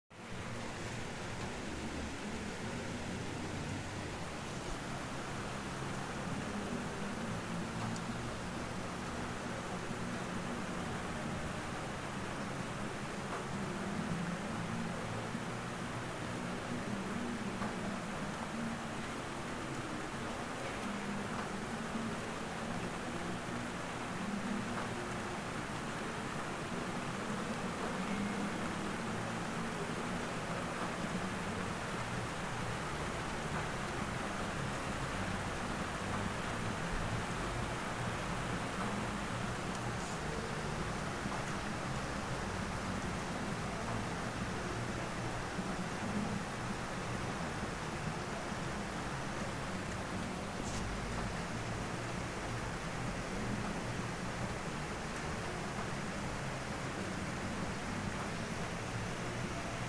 Lluvia en Alajuela ALAJUELA